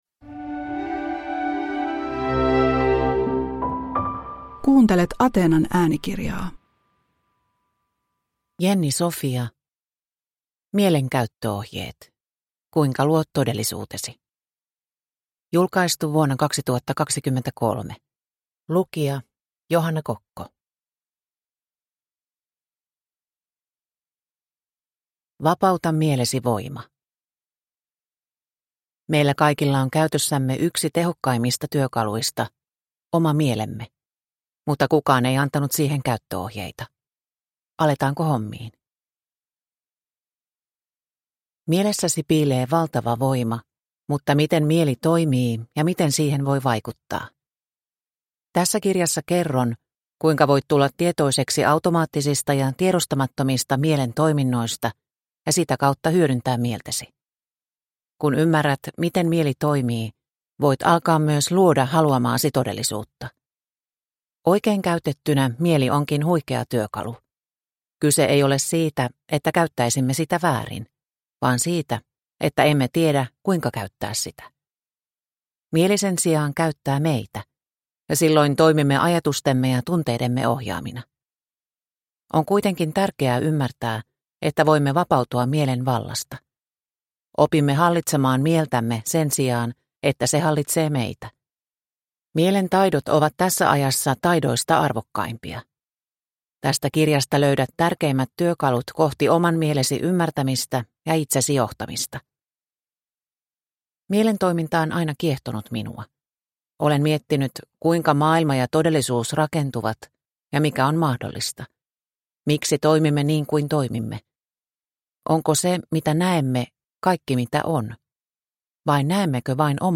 Mielen käyttöohjeet – Ljudbok – Laddas ner